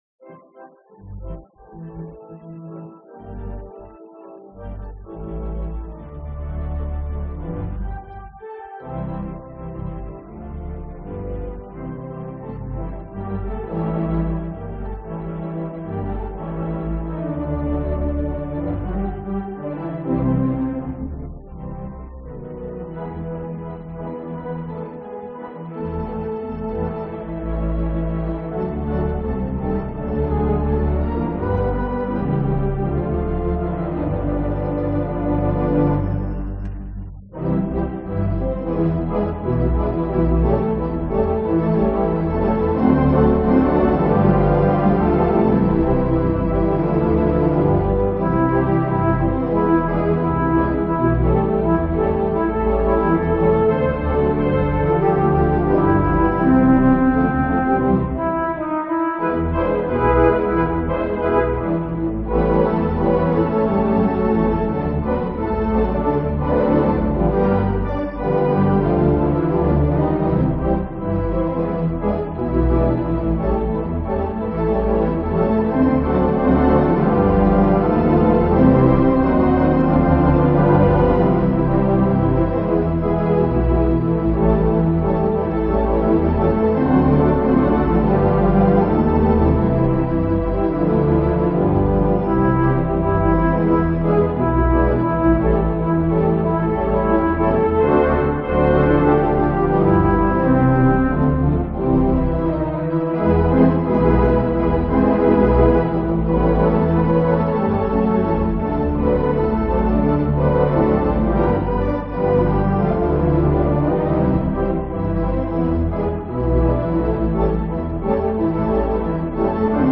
Tags: VTPO Theatreorgan jorgan miditzer theatre